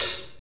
HAT F T LH0L.wav